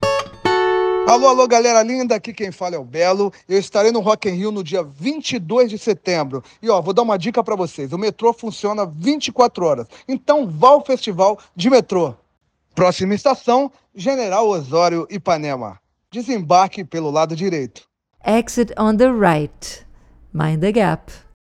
Os artistas gravaram mensagens anunciando o nome das estações e o funcionamento 24 horas do sistema metroviário durante os sete dias de evento.
ZS_MetroRio_Belo_Gen.osorio-3.wav